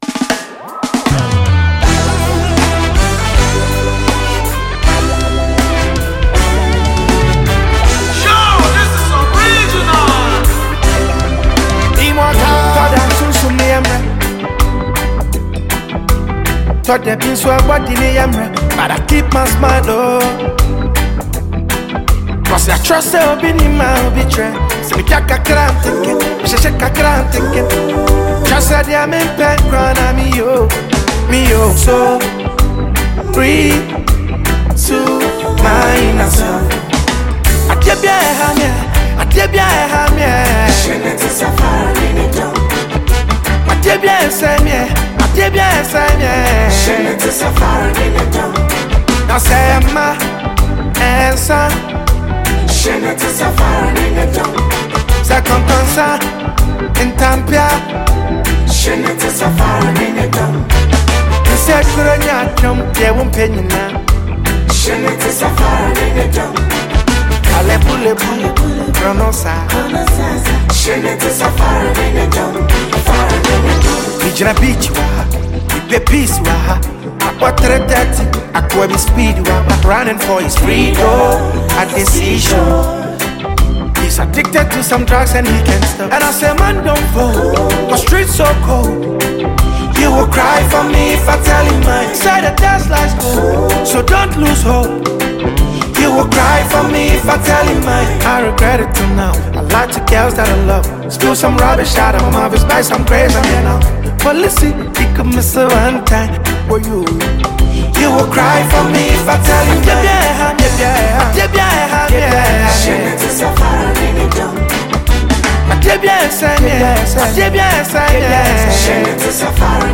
New tune from rapper/singer